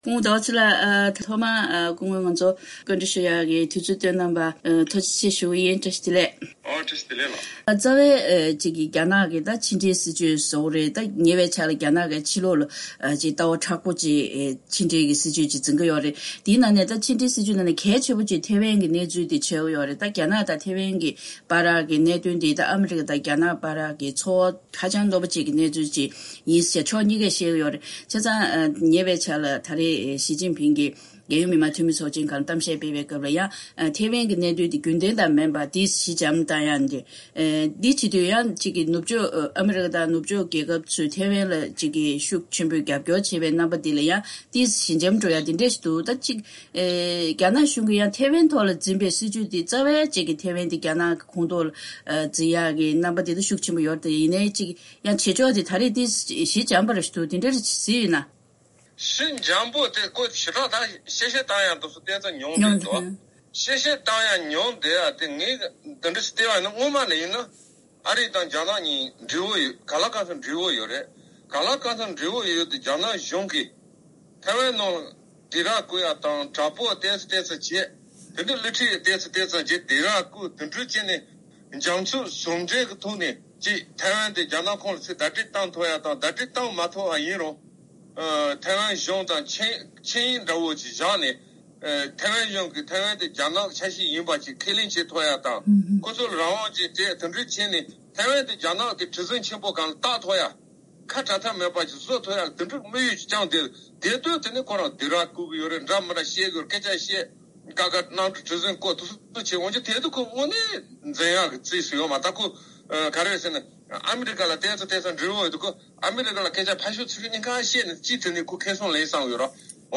བཀའ་དྲི་ཞུས་པ་ཞིག་སྙན་སྒྲོན་ཞུས་གནང་གི་རེད།